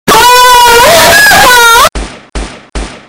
UWU+BANG-BANG-BANG - Botão de Efeito Sonoro